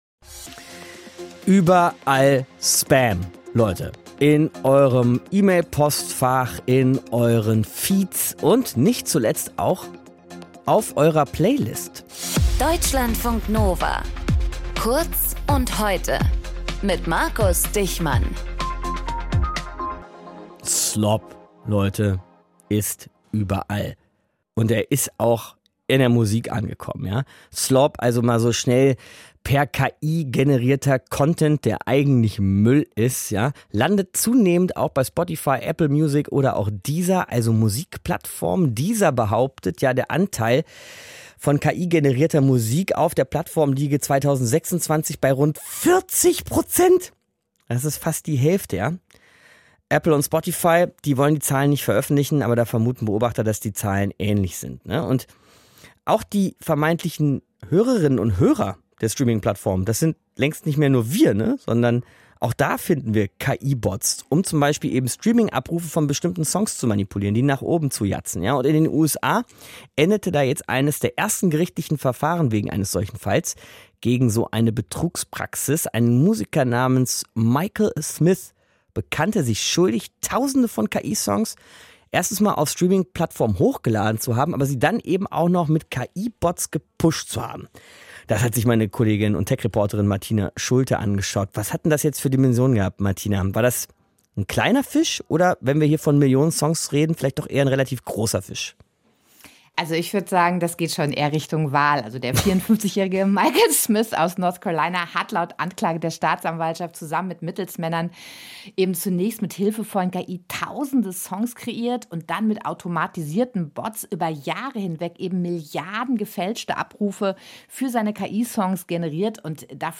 In dieser Folge mit:
Moderation: